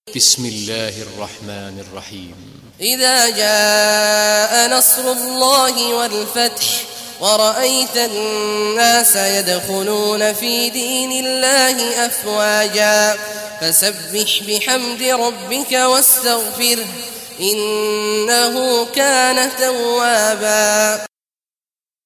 Surah Nasr Recitation by Sheikh Awad al Juhany
110-an-nasr.mp3